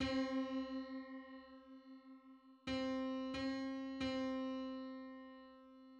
Just: 51 : 50 = 34.28 cents.
Licensing [ edit ] Public domain Public domain false false This media depicts a musical interval outside of a specific musical context.